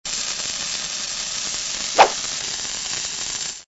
TL_dynamite.ogg